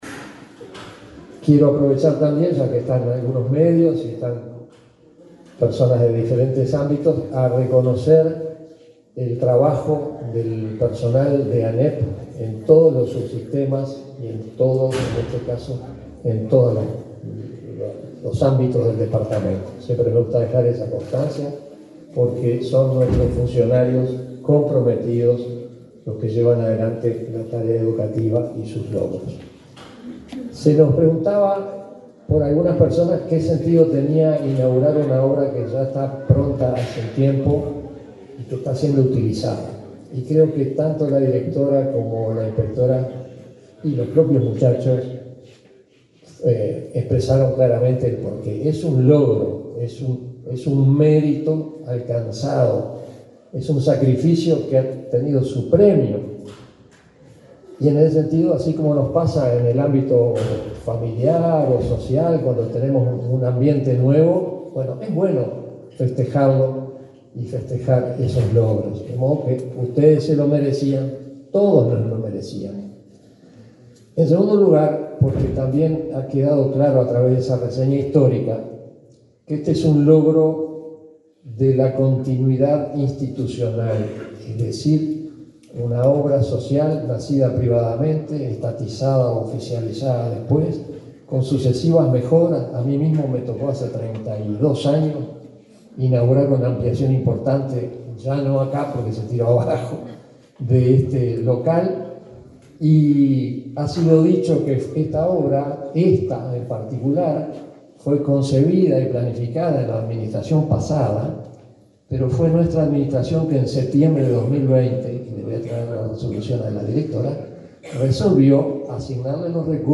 Palabras del consejero de la ANEP, Juan Gabito Zóboli
Palabras del consejero de la ANEP, Juan Gabito Zóboli 24/05/2024 Compartir Facebook X Copiar enlace WhatsApp LinkedIn El consejero de la Administración Nacional de Educación Pública (ANEP), participó, este viernes 24, de la inauguración de la ampliación y remodelación del liceo Cabo de Santa María del balneario La Paloma, en el departamento de Rocha.